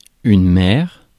Ääntäminen
France (Paris): IPA: [yn mɛʁ]